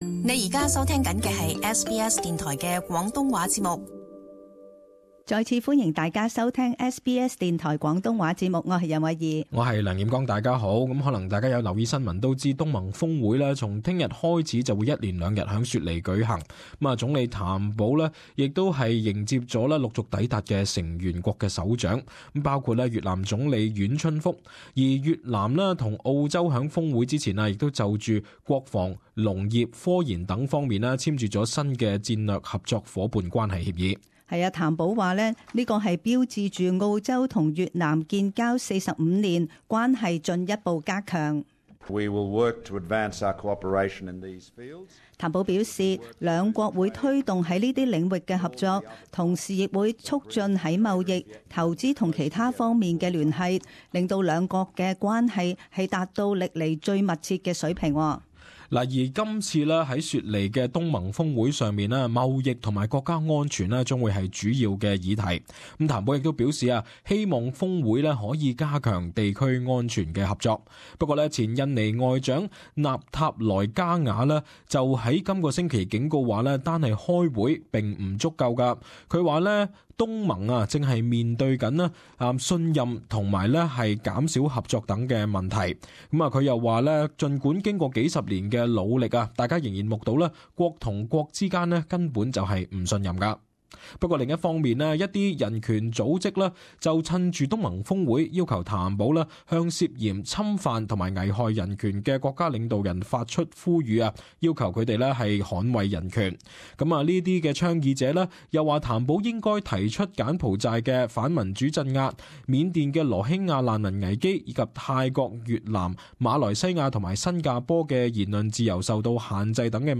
【时事报导】雪梨东盟峰会